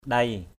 /ɗeɪ/ (trtr.) quá, lắm = très, excessivement. lo ndei _l% Q] nhiều lắm = beaucoup. jhak ndei JK Q] xấu lắm, xấu quá = très mauvais. mada ndei md% Q]...